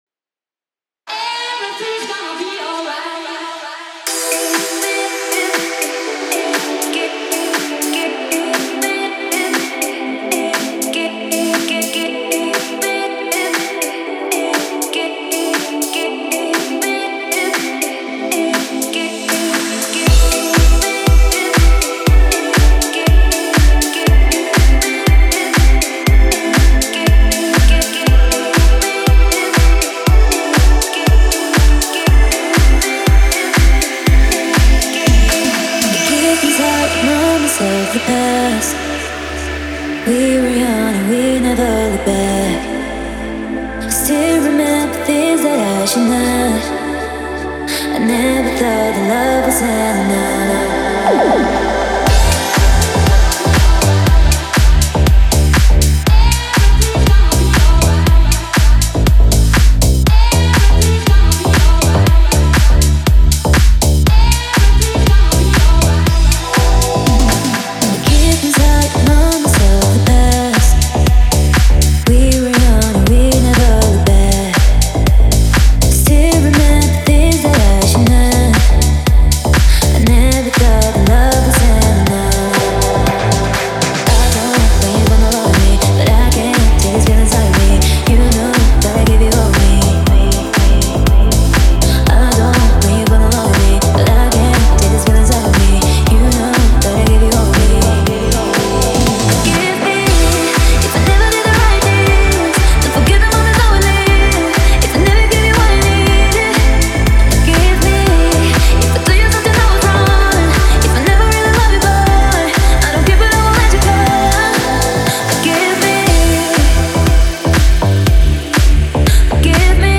это трек в жанре электронного попа